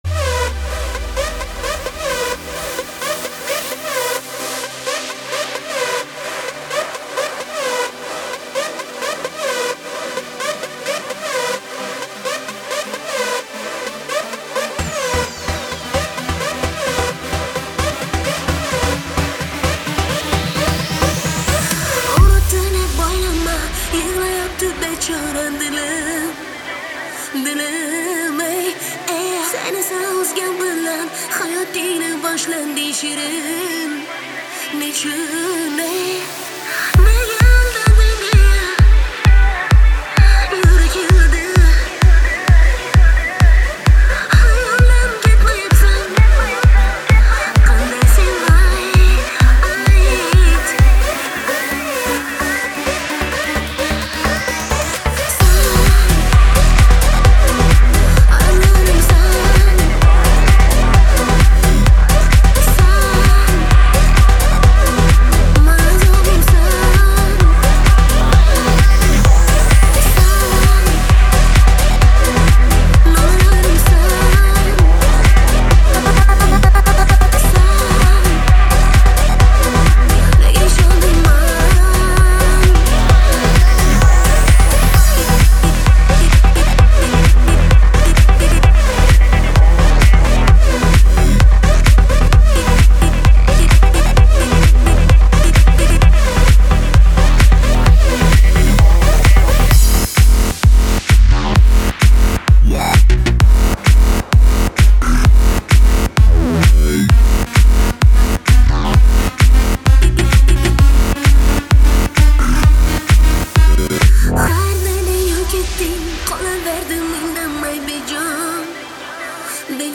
Трек размещён в разделе Узбекская музыка / Поп / 2022.